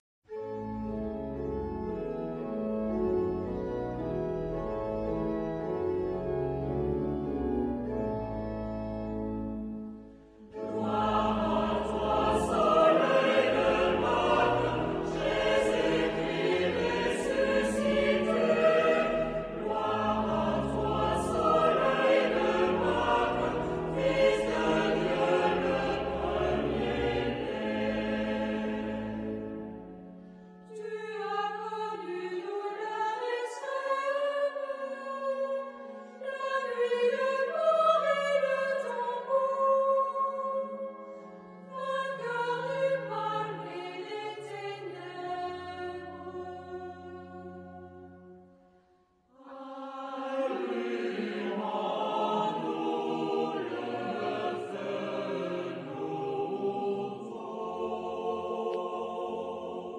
Género/Estilo/Forma: Cántico ; Sagrado
Tipo de formación coral: SATB  (4 voces Coro mixto )
Tonalidad : la mayor